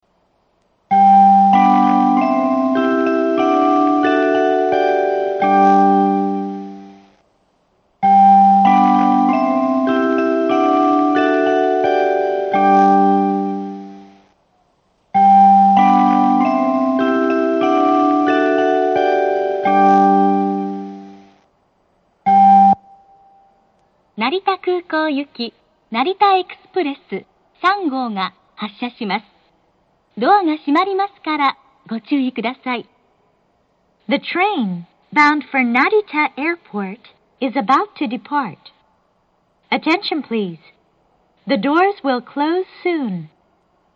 放送装置更新以前は、駅員放送が被るとこのようにメロディーまでかき消されてしまいましたが、現在ではこのようにメロディーはかき消されません。
下り発車メロディー 発車放送は成田エクスプレス３号成田空港行です。